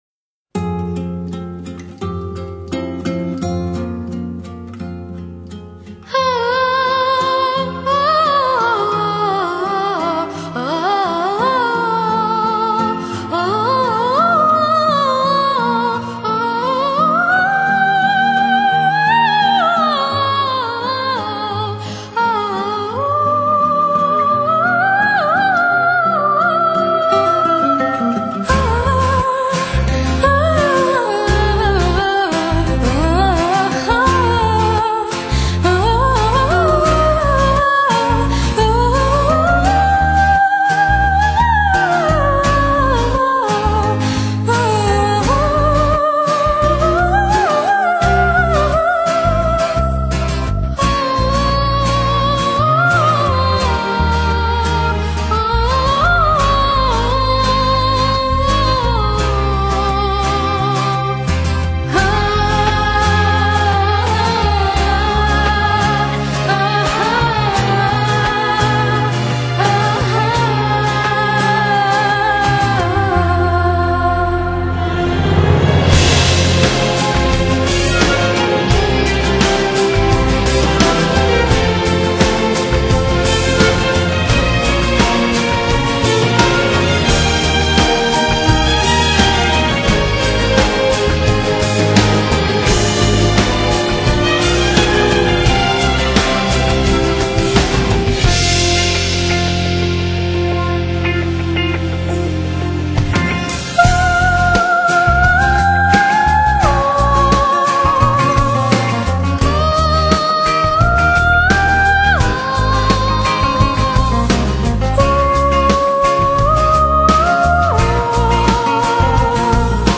只是两个曼妙的童声在音乐的衬托下穿梭游离的